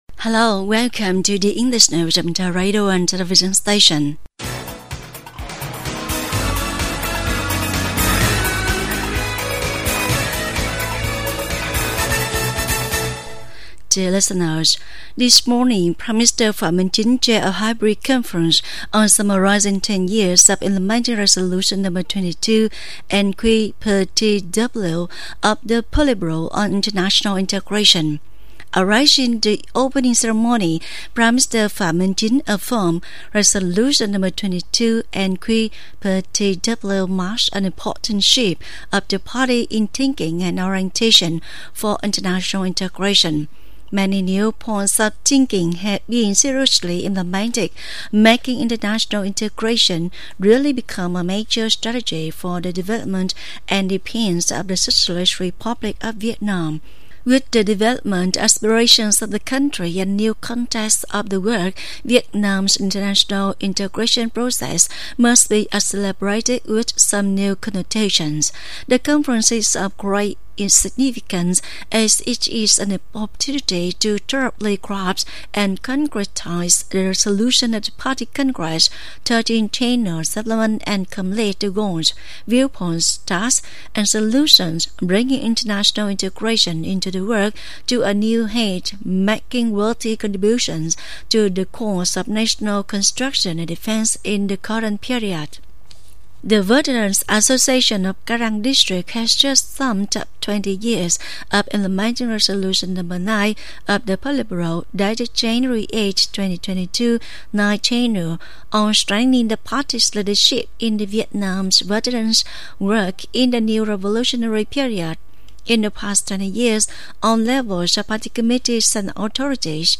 Bản tin tiếng Anh 2/8/2023